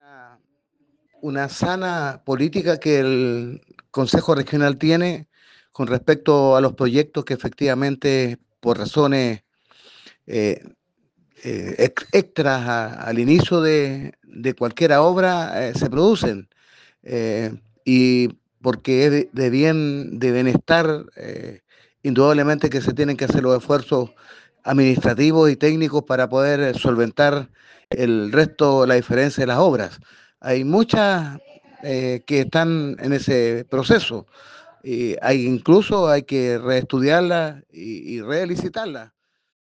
Para el consejero Lombardo Toledo